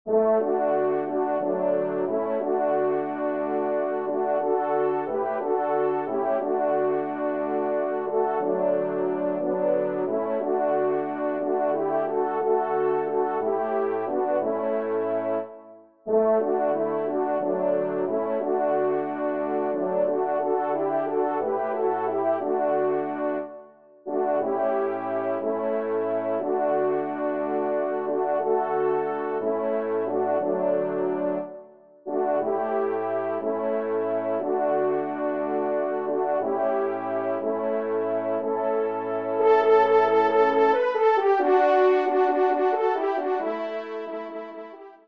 TON SIMPLE :
ENSEMBLE